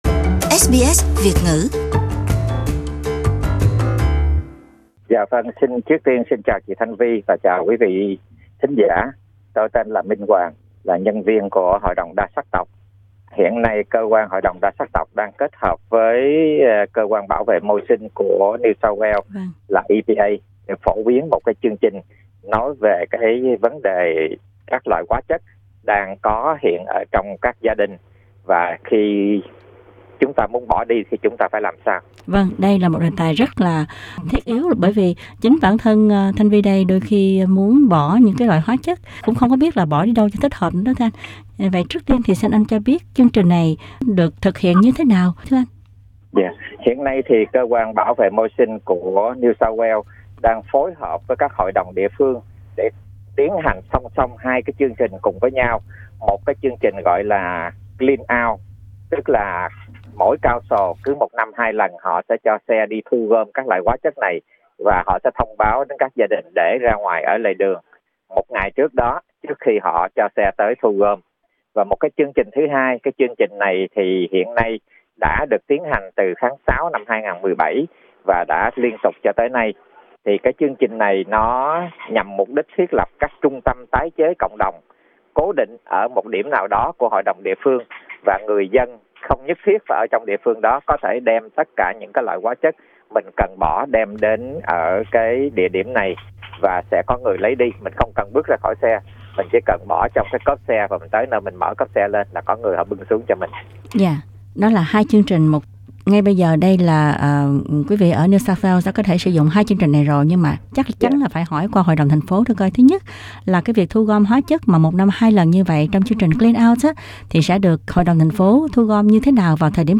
Trong phần phỏng vấn ở đầu trang